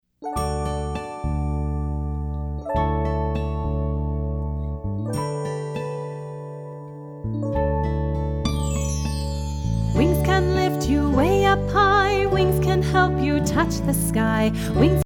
hand clappin', foot stompin' fun for everyone!